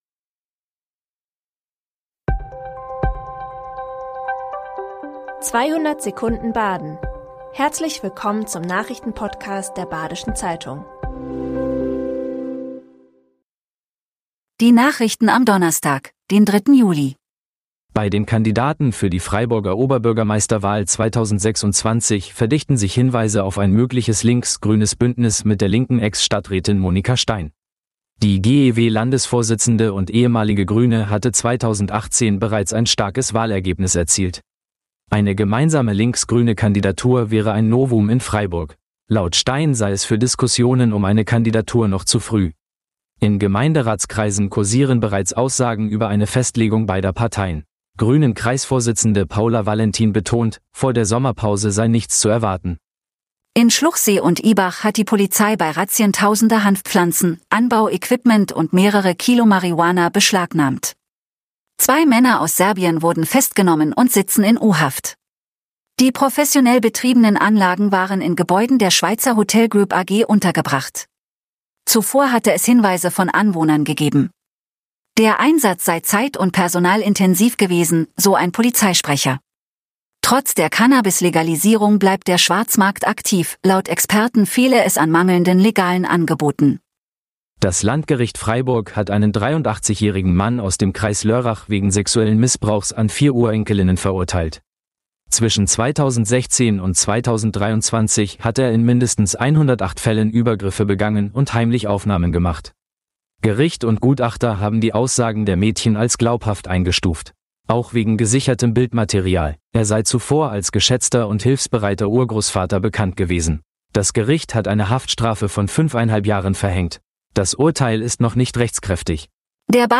5 Nachrichten in 200 Sekunden.
Nachrichten